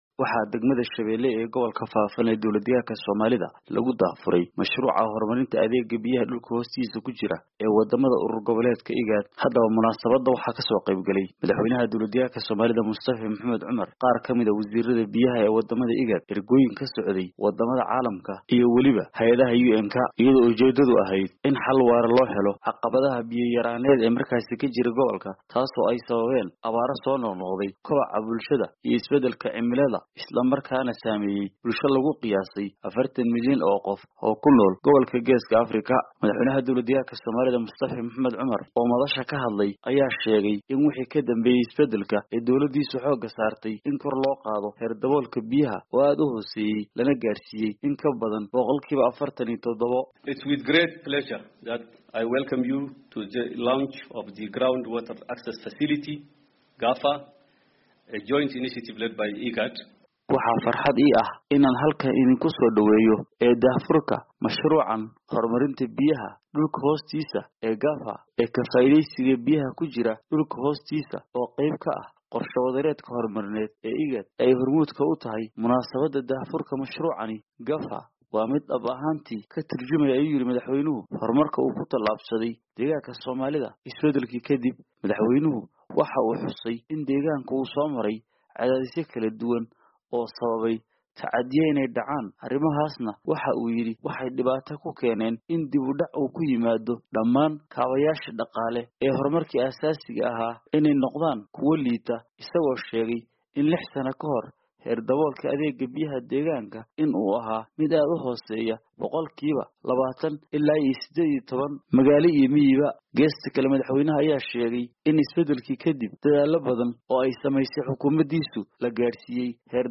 Warkan waxaa magaalada Jigjiga kasoo diray